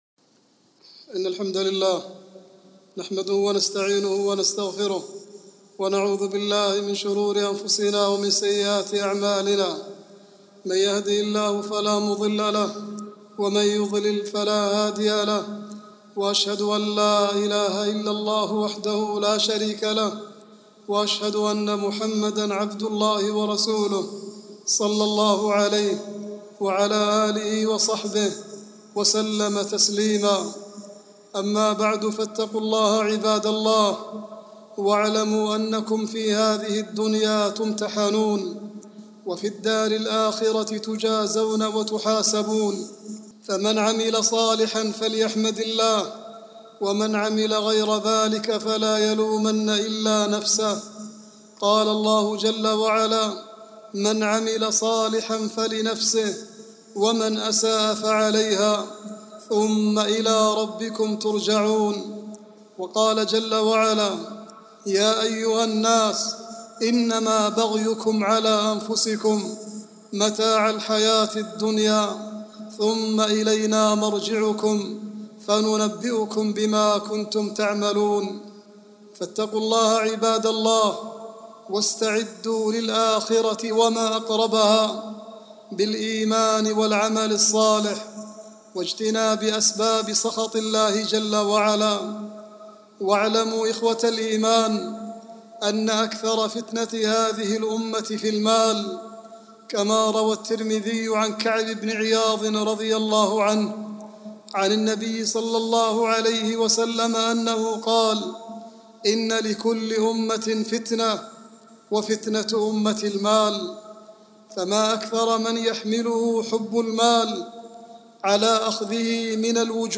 العنوان : خطر الفساد المالي، وموقف المسلم من الموقوفين بتهمته (خطبة صوتية)